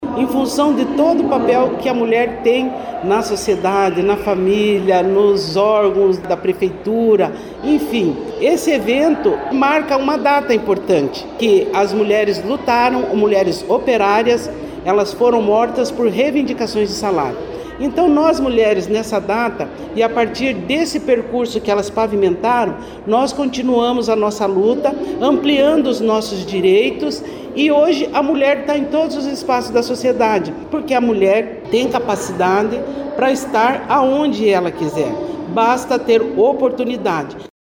Marli Teixeira, secretária da Mulher e Igualdade Étnico-Racial de Curitiba, falou sobre a importância de lançar um programa em uma data que celebra tantas conquistas para o público feminino.